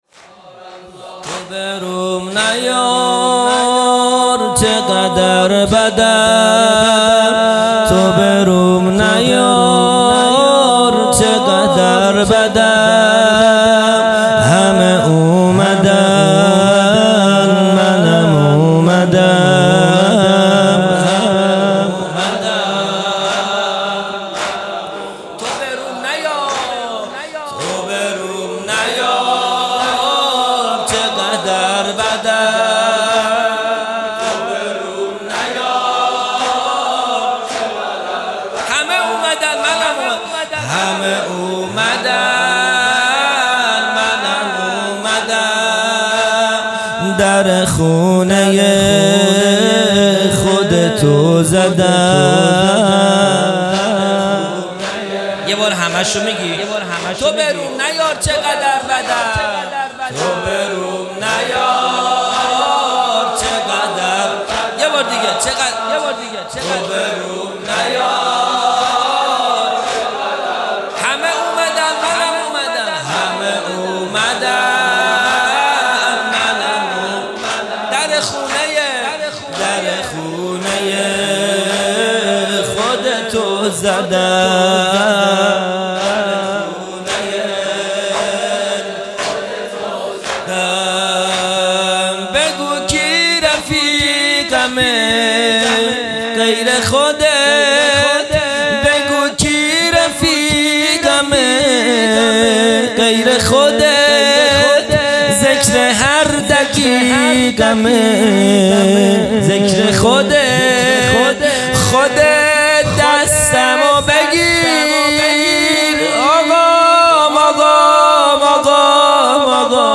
0 0 زمینه | تو به روم نیار چقدر بدم
جلسۀ هفتگی